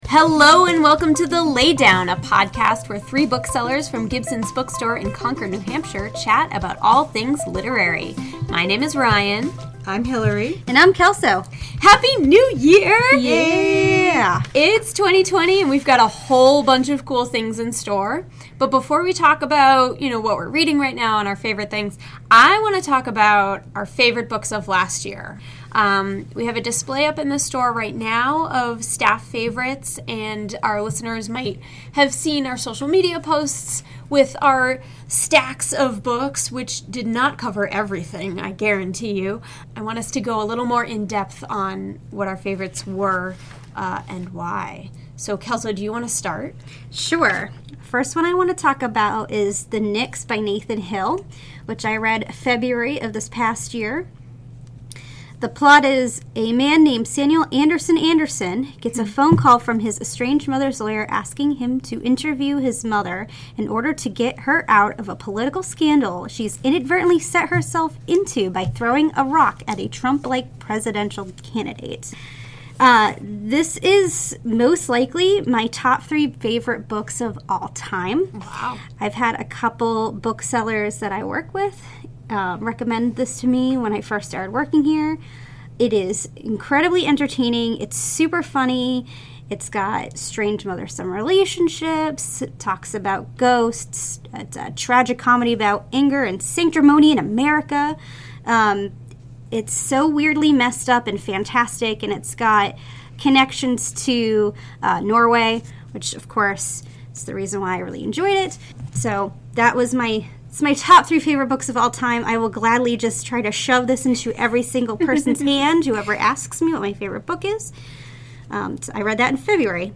We're looking back at 2019 and looking ahead to 2020, with a bonus clip from our interview with Erin Morgenstern!